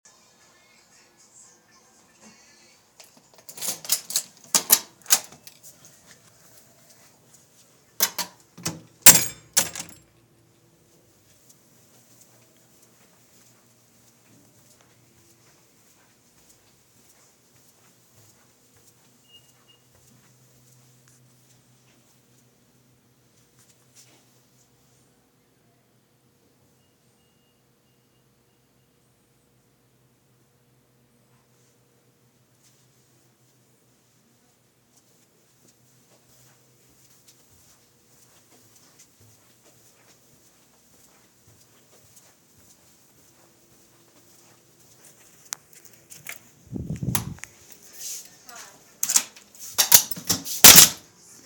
Field Recording 9
Location: Nassau Dorm, second floor
Sounds Heard: Door opening and closing, footsteps, elevator pings.